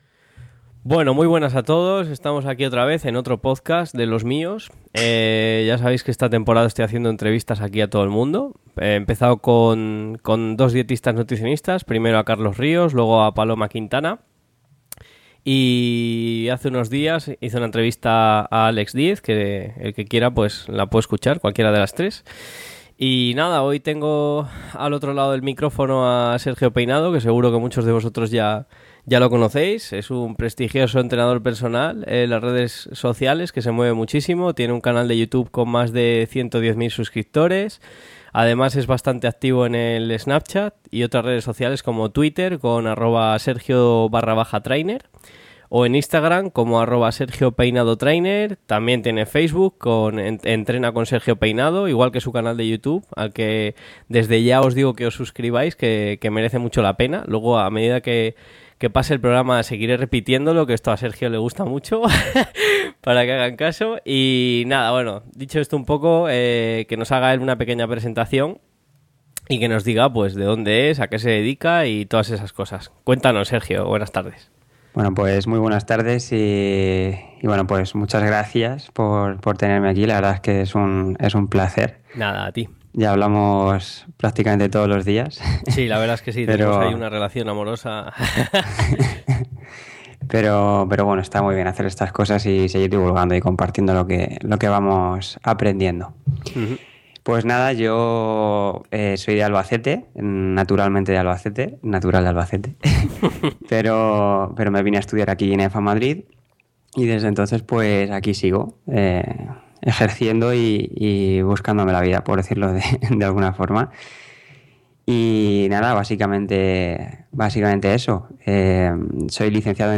Esperamos que disfrutéis tanto de la entrevista como nosotros lo hemos hecho mientras grabábamos y sobre todo que aprendáis algo nuevo, algunos de los puntos que hemos tratado son: